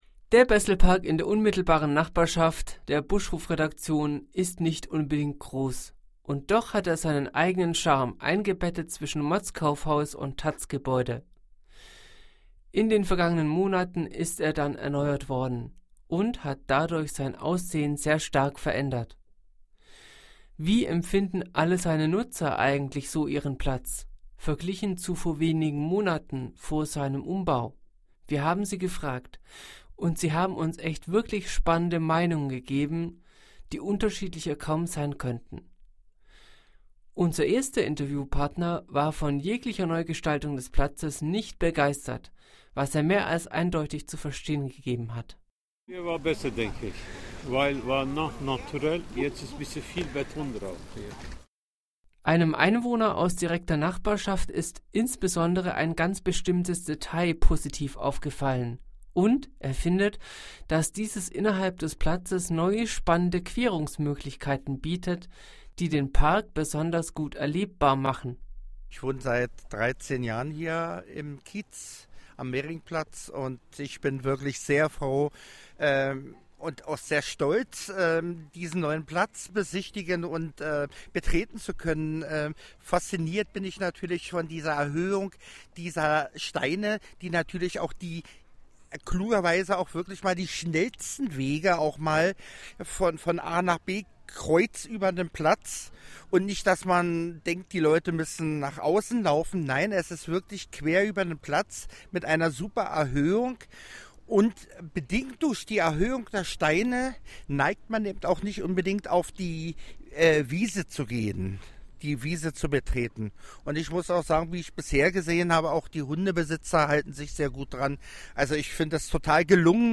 Und wie den Menschen der neue Besselpark so gefällt und welche Meinung sie jetzt von ihm haben hört Ihr hier.